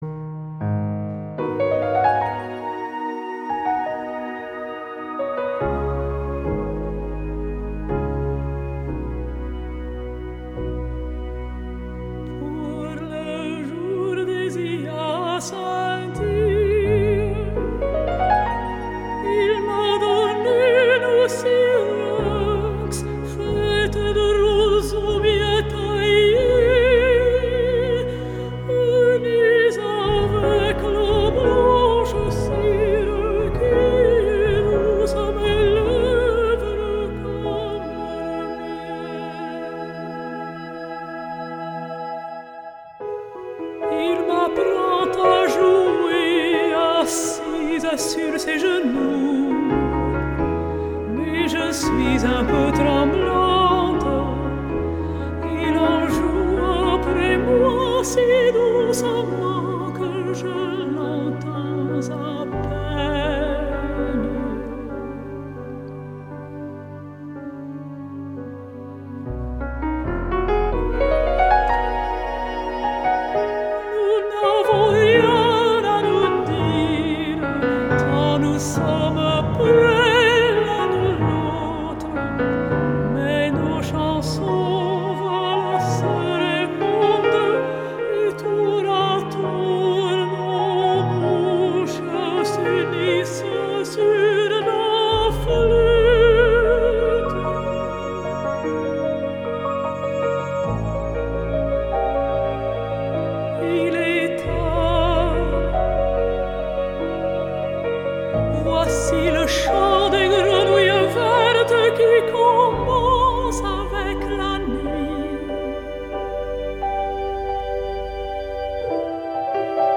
Классическая музыка чудесно обрамляет работу!!!;))
Прекрасно ... только слов в песне не разобрал, по ненашенски поет ....